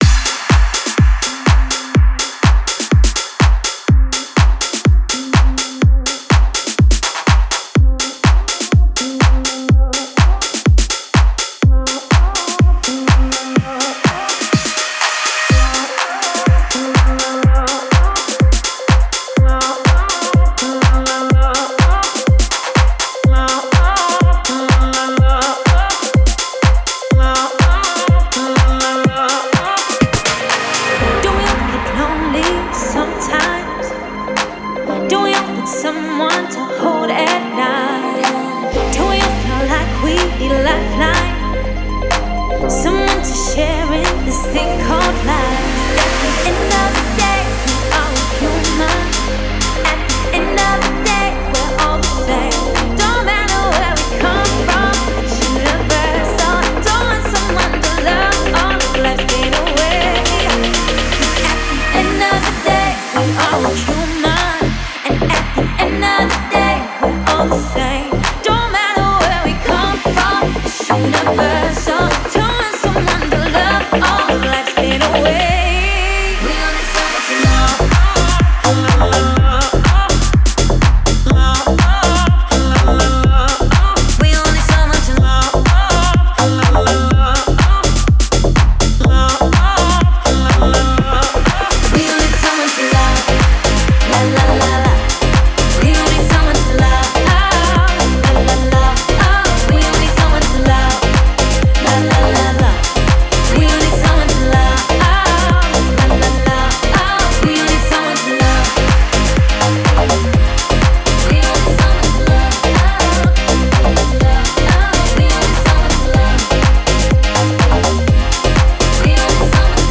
это энергичный трек в жанре EDM